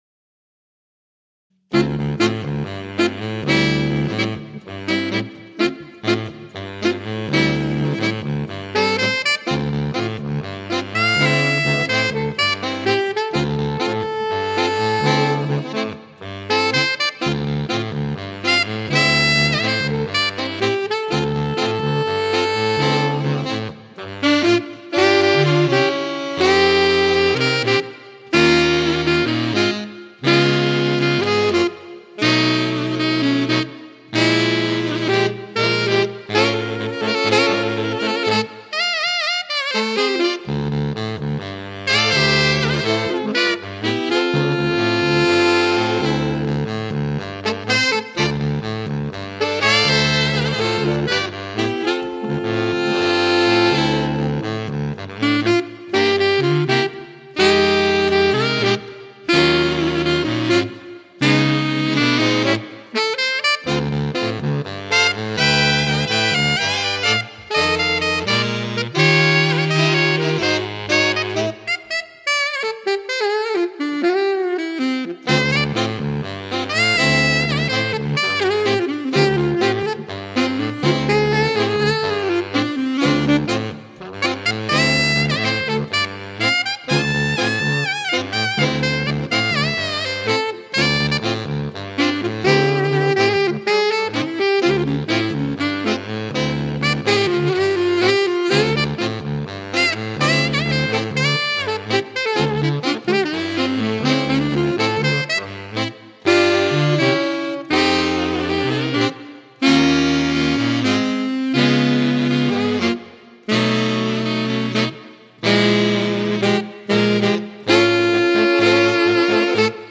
Another catchy and groove propelled original
complete with cool “super sleuth”-styled bass riff
Ranges: Alto 1: F3. Tenor: D3. Baritone: B1
Detective Music